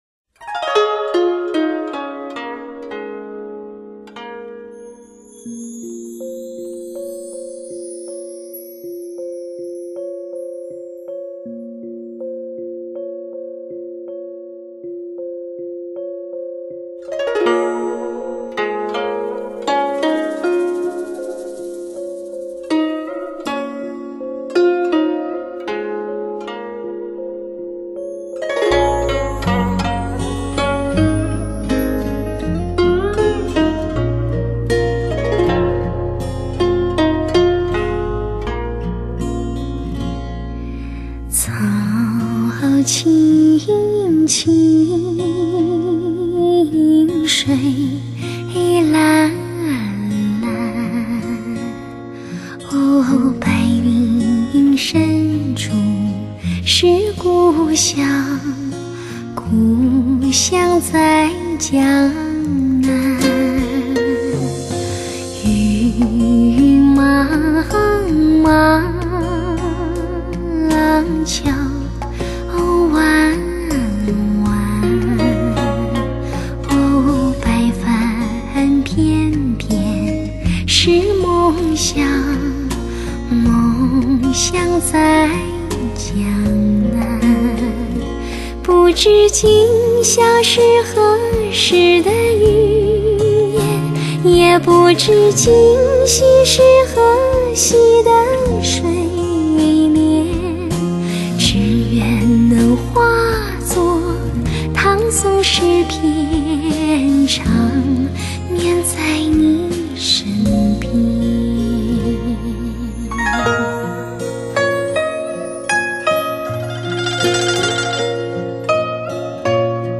花样小调透出江南别致情趣
精良制作打造流行发烧新格调